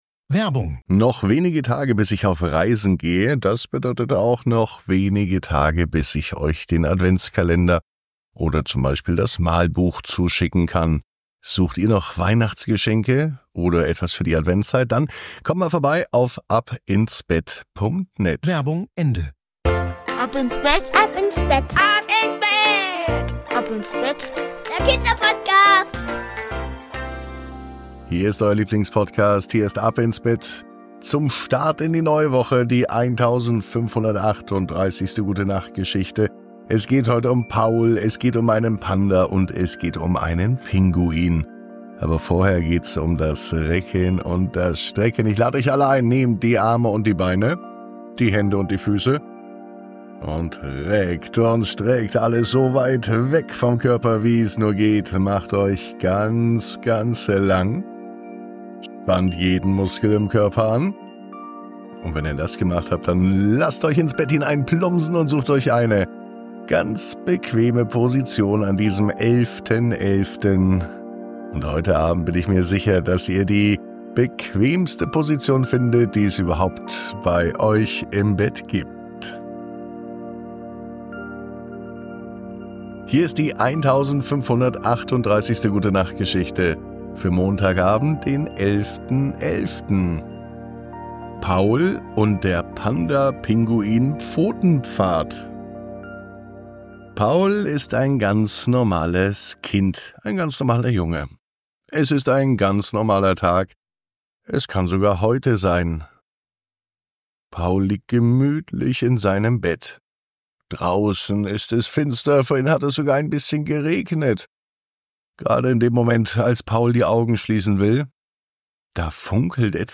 Diese Reise zeigt Paul, dass Freundschaft und Fantasie wahre Wunder erschaffen können – eine beruhigende Geschichte, die sanft in die Welt der Träume führt.